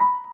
piano_last30.ogg